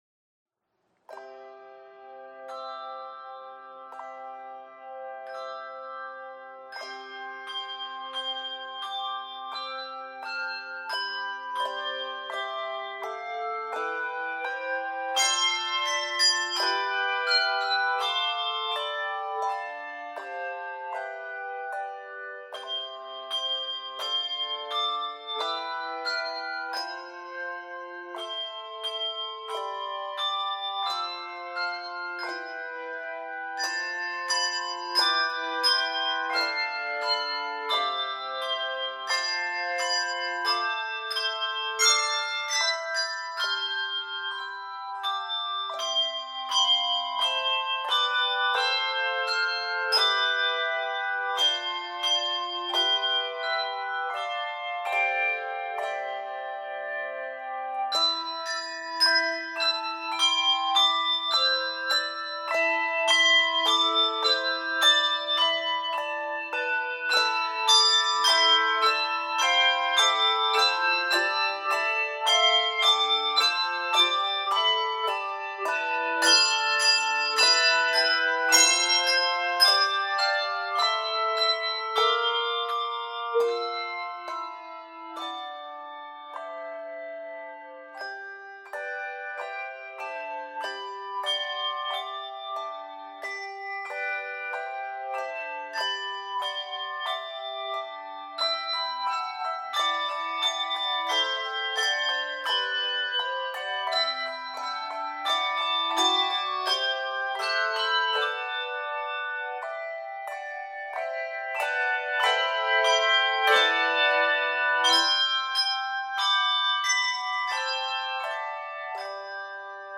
Keys of C Major, a minor, and G Major.
Appalachian Folk Song Arranger